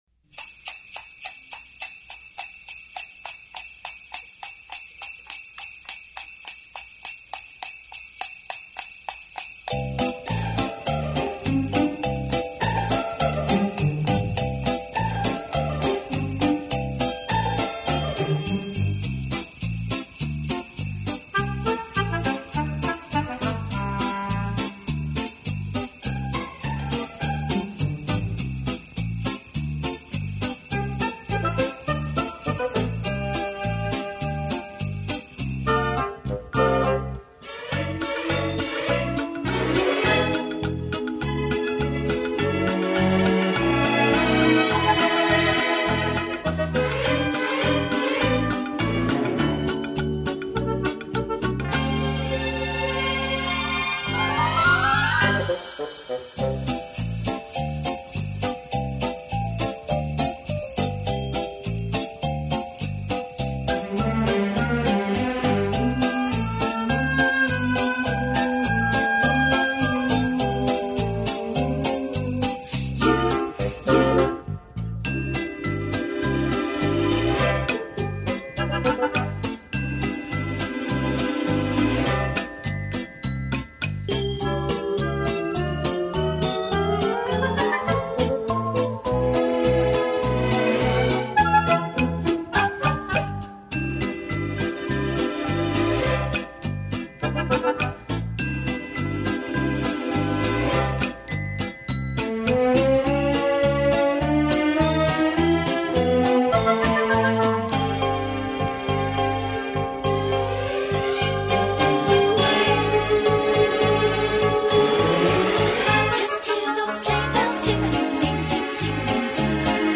santa-songs_long-ver.mp3